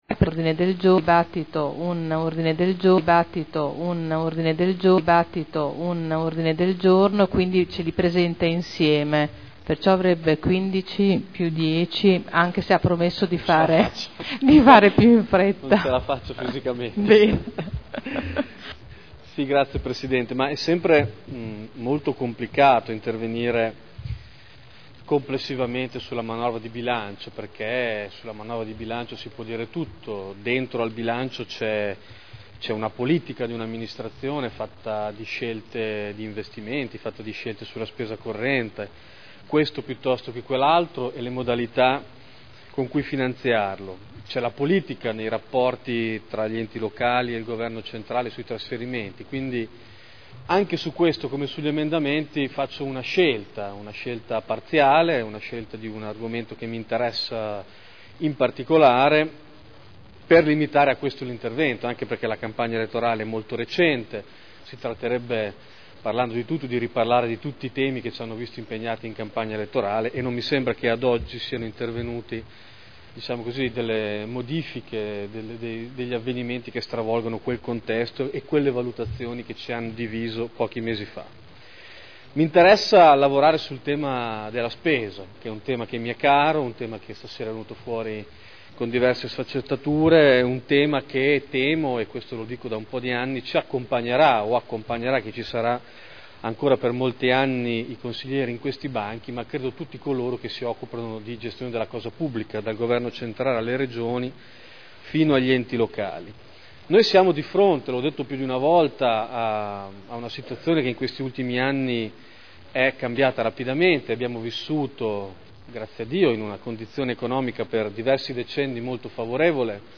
Davide Torrini — Sito Audio Consiglio Comunale
Dibattito sul bilancio